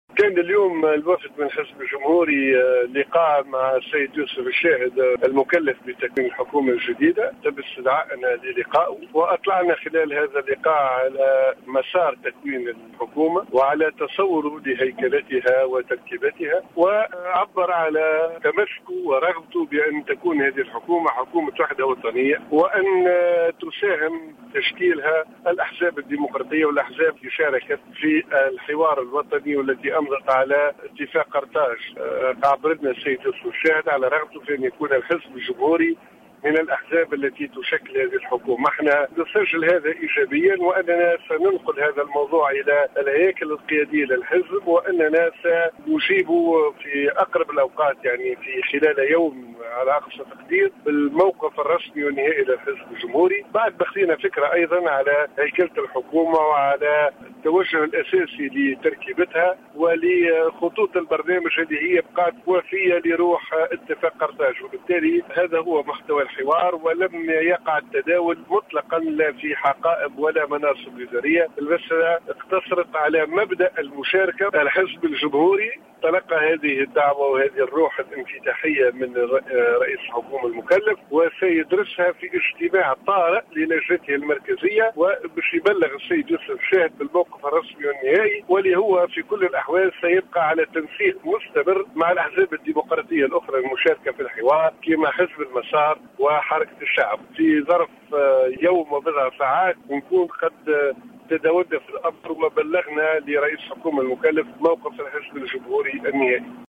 أكد القيادي في الحزب الجمهوري عصام الشابي في اتصال هاتفي بالجوهرة أف أم، اليوم الخميس أن رئيس الحكومة المكلف، يوسف الشاهد، قد عبر خلال لقائه وفدا من الحزب عن رغبته في أن يكون الجمهوري ممثلا في الحكومة القادمة.